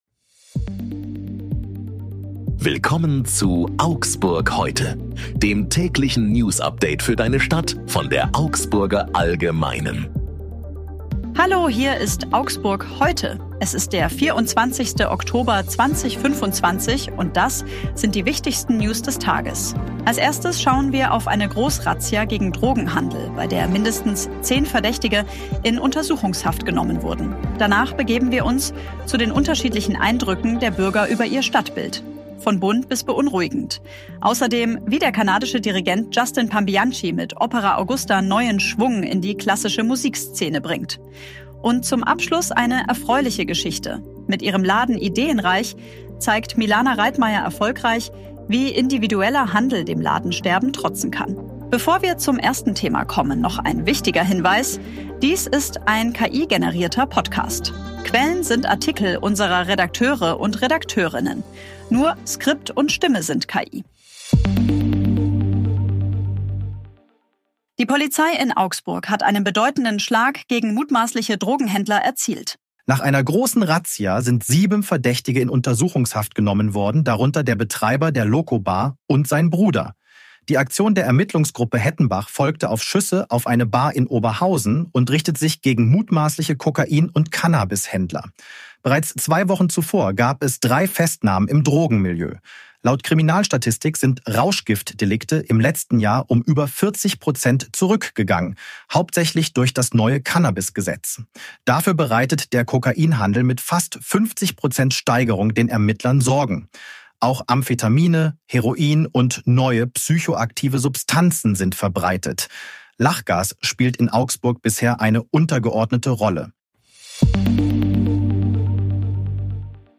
Hier ist wieder das tägliche Newsupdate für deine Stadt.
Nur Skript und Stimme sind KI.